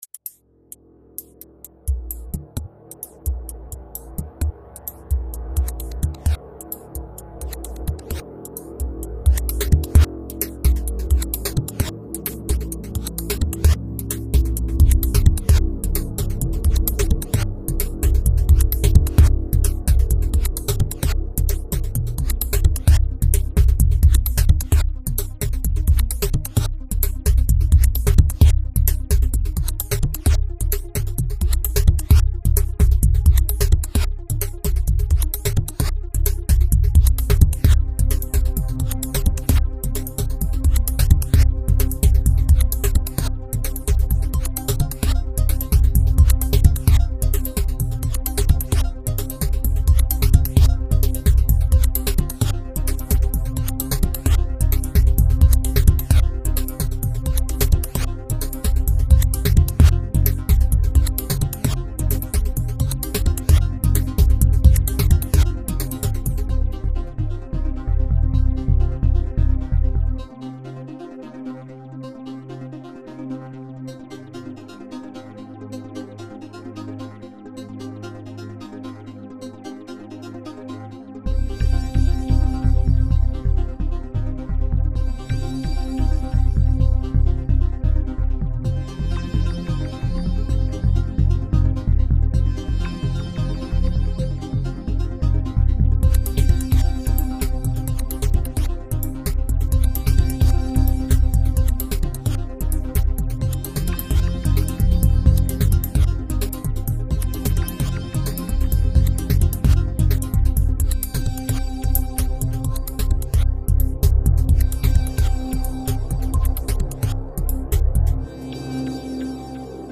komponieren seit 1995 mit Yamaha's Control-Synthesizer CS1x, Cubase VST und FrootyLoops